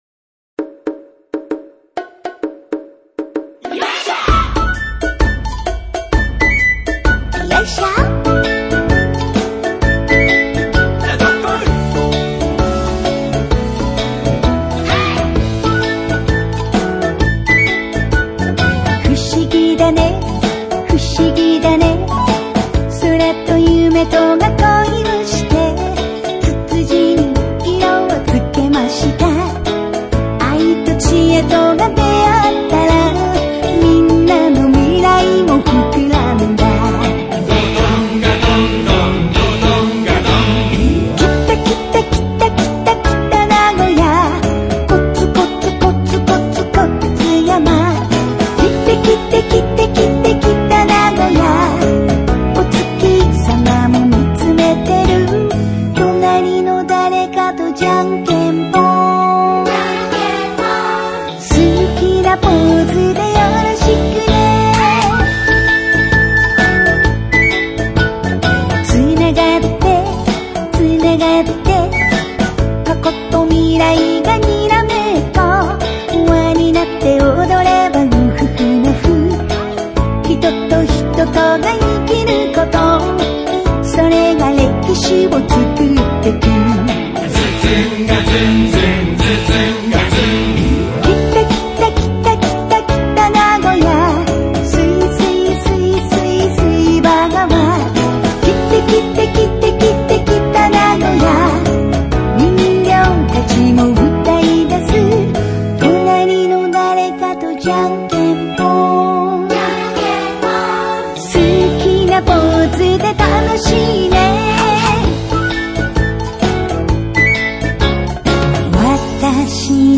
子どもからお年寄りまで世代を超えて歌って、踊れる楽しい音頭です。